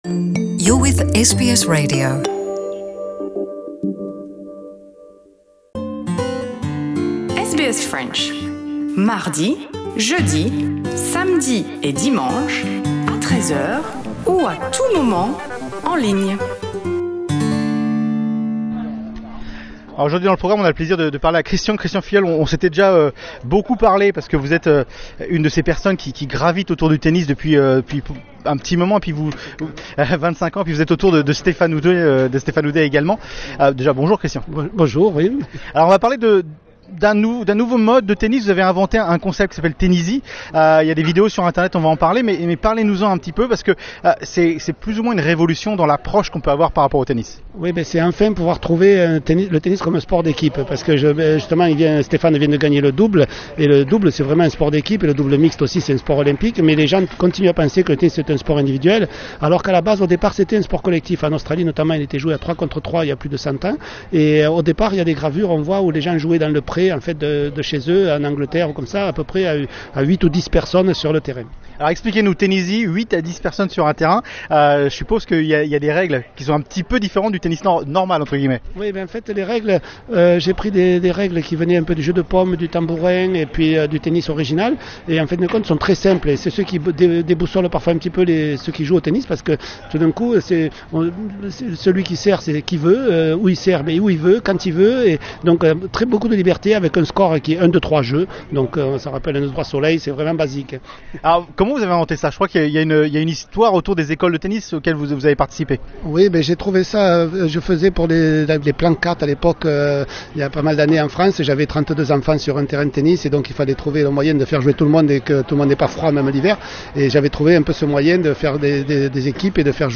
et elle s'appelle Tenisy Ecoutez sont interview plus haut